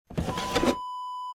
Dresser Drawer Open Wav Sound Effect #2
Description: The sound of a wooden dresser drawer being opened
Properties: 48.000 kHz 16-bit Stereo
A beep sound is embedded in the audio preview file but it is not present in the high resolution downloadable wav file.
Keywords: wooden, dresser, drawer, pull, pulling, open, opening
drawer-dresser-open-preview-2.mp3